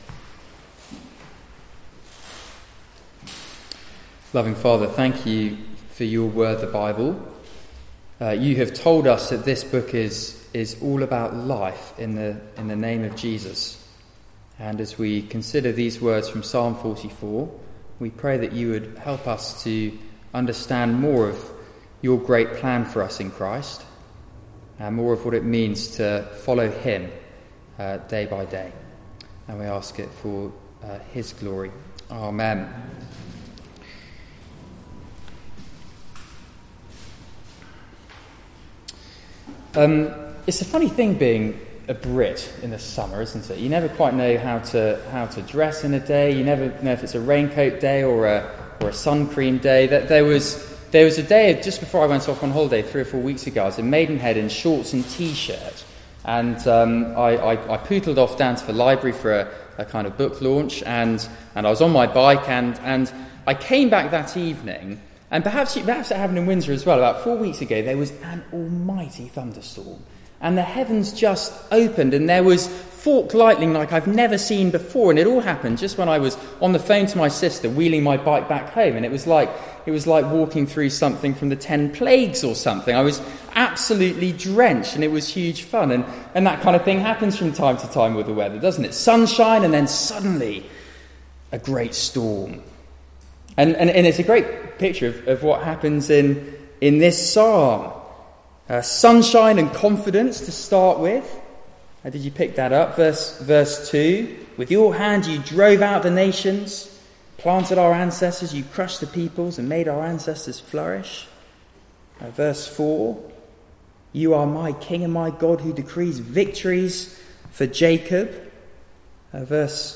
Passage: Psalm 44 Service Type: Weekly Service at 4pm Bible Text